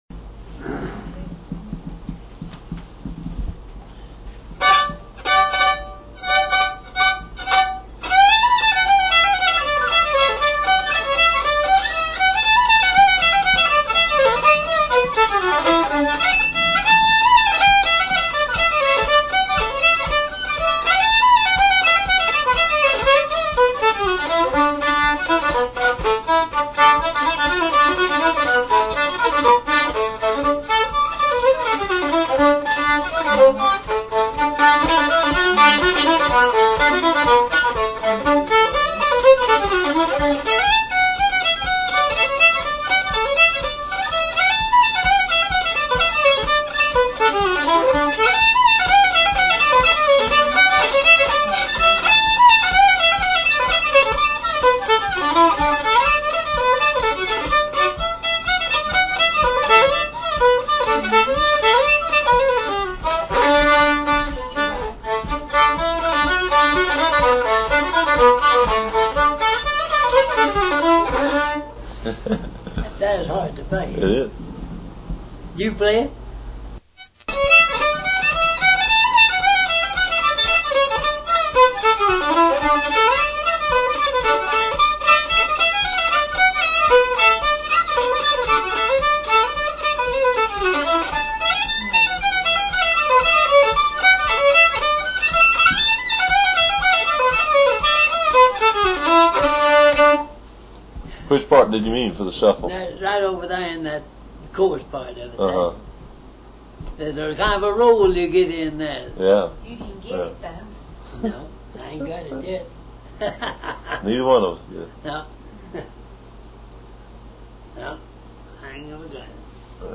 Instrument: fiddle
Key: D
Meter: 4/4
Strains: 3 (high-low-higher octave)
Rendition: 1r-2r-1r-3-2-(break)-1-3-1
Phrase Structure: ABAC QRQC UVUC (abcd abef qrss' uvwx uvef)
Compass: 15